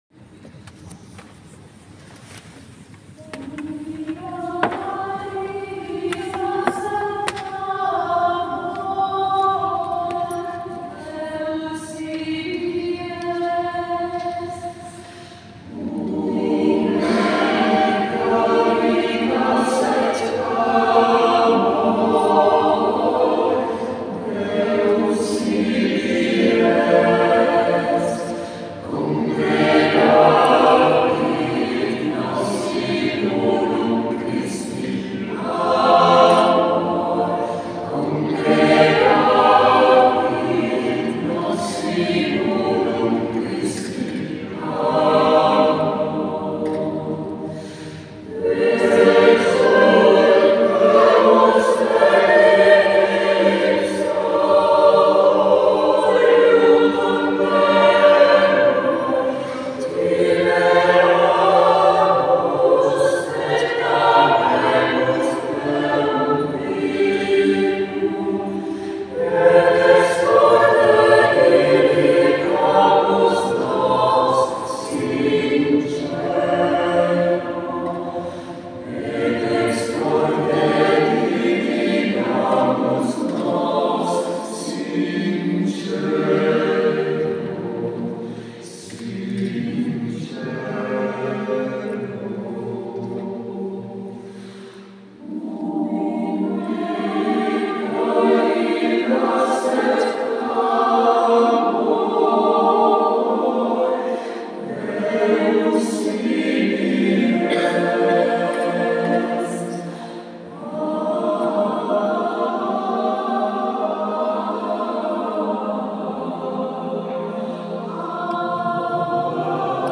Concerto del 19 novembre 2022 – Chiesa di San Bartolomeo a Treviso